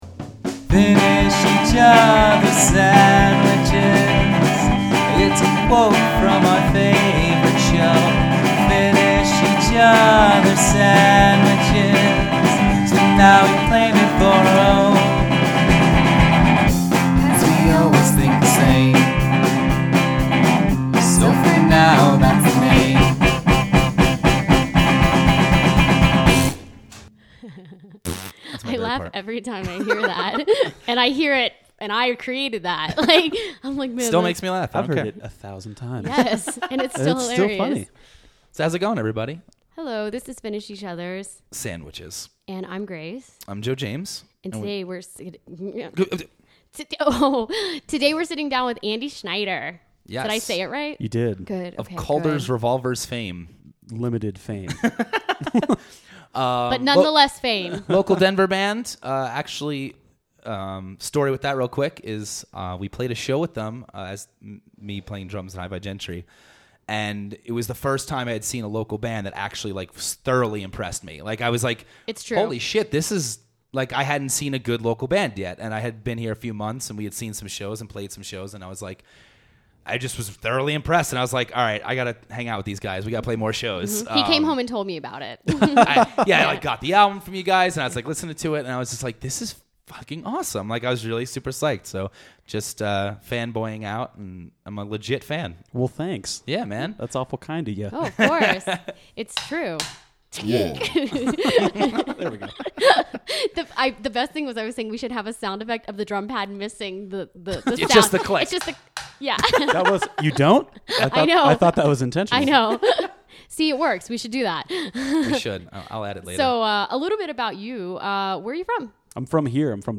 Hello ladies and gentlemen!